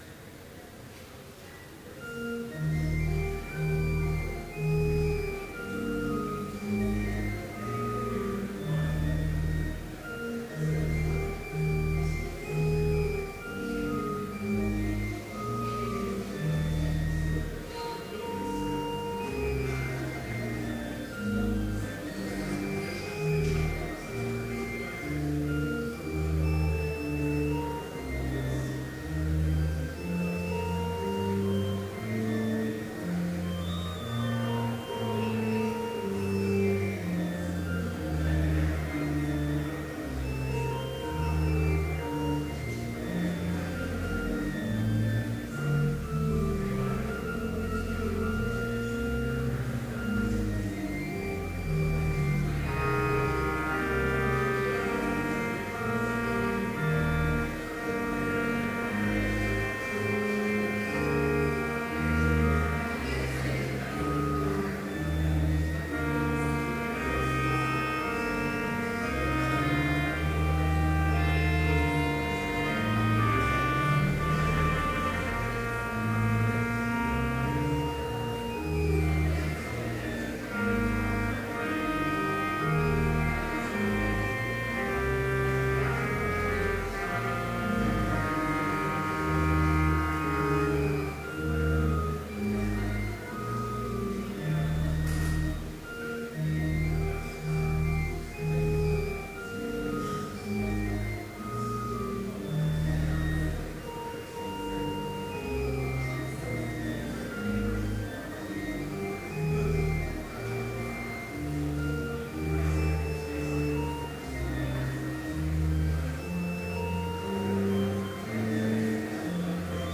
Complete service audio for Chapel - October 23, 2012